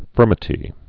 (fûrmĭ-tē)